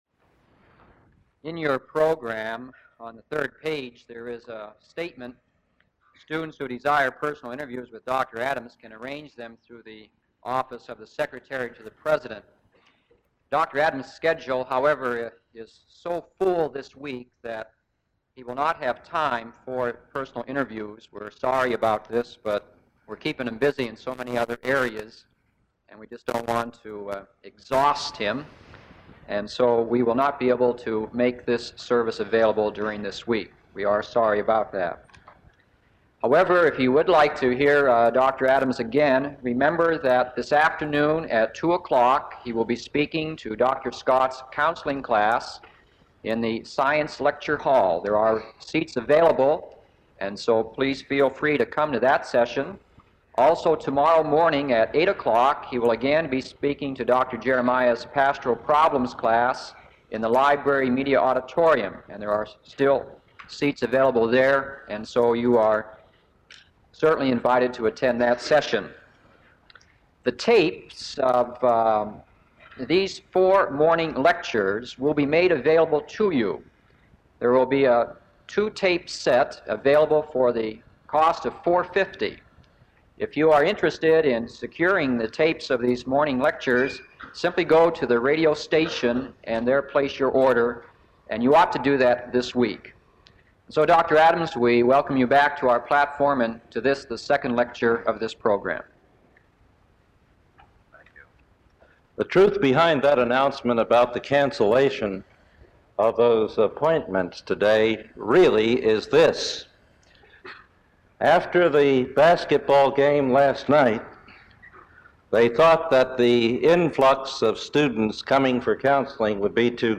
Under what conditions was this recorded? four different lectures on the Scriptures and Counseling at the 3rd Annual Staley Distinguished Christian Scholar Lectureship Program at Cedarville College